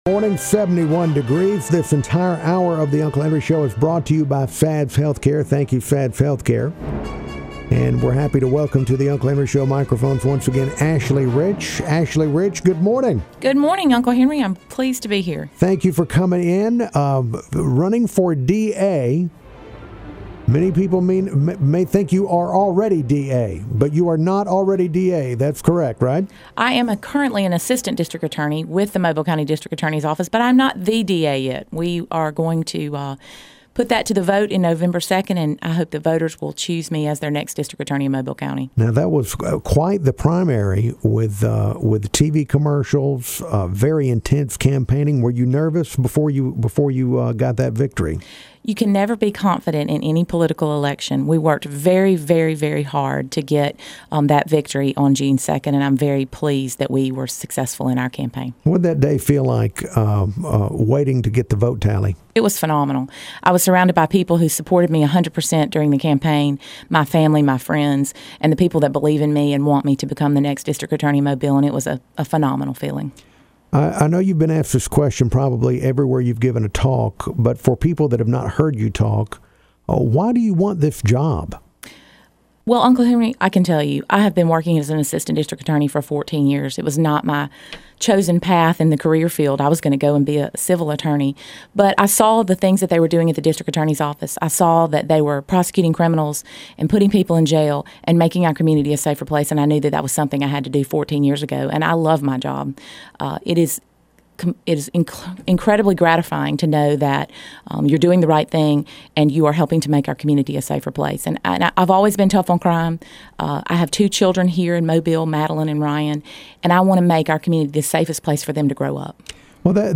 Sept.16 7am hourThu, 16 Sep 2010 10:58:28 -0400 (if the link doesn't work, this one should:Ashley Rich Radio Interview and/or blog/wp-content/uploads/2012/07/u7am0916AshleyRich.mp3 )